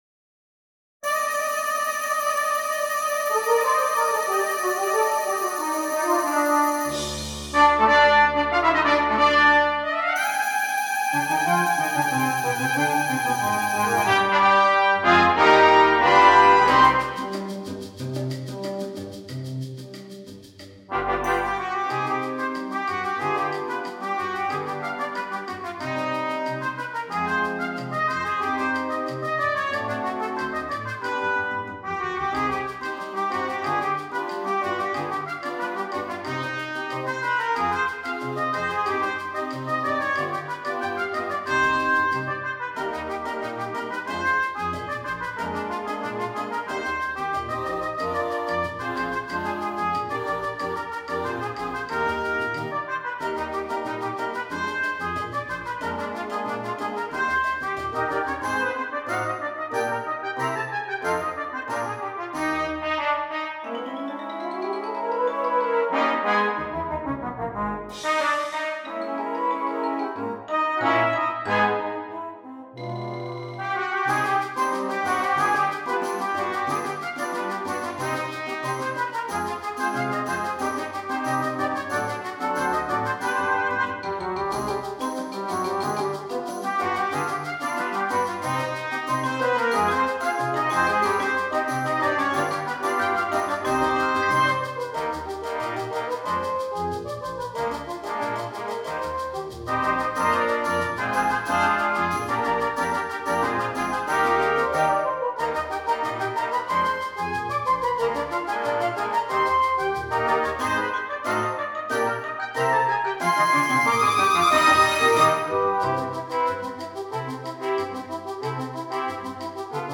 10 Trumpets, Tuba and Percussion